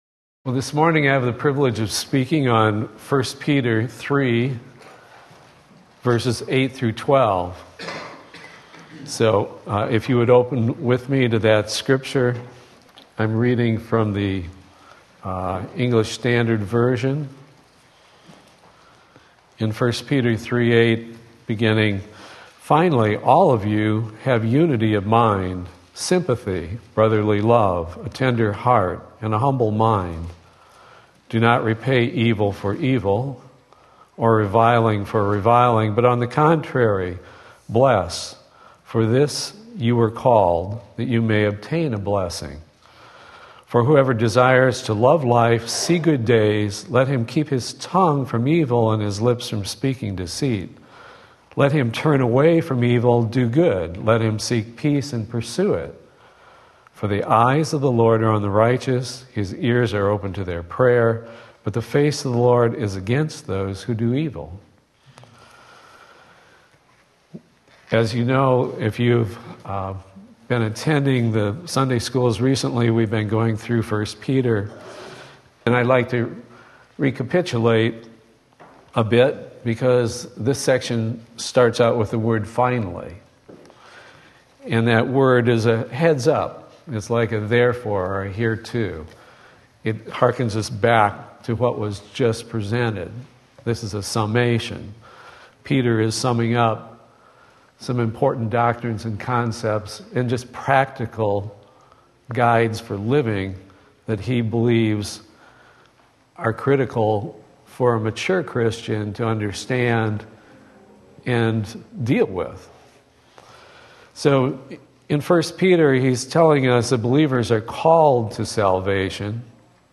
Sermon Link
Sunday School